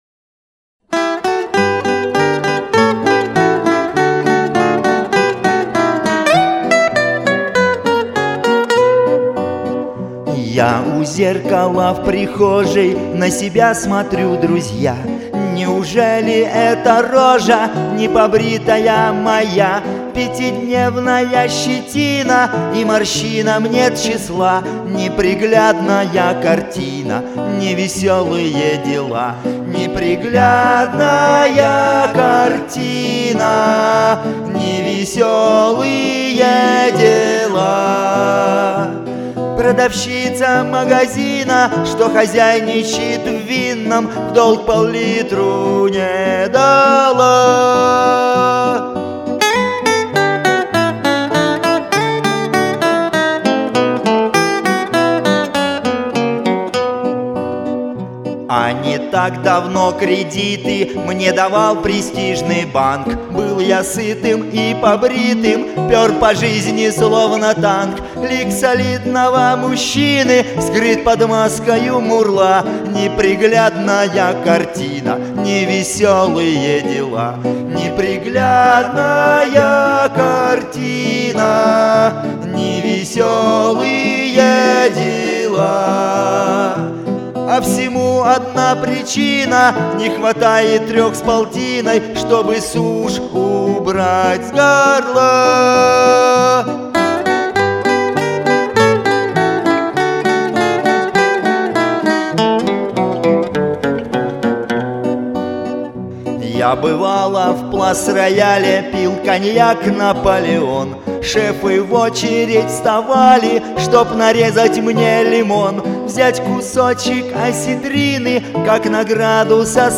• Песня: Поэзия, Юмор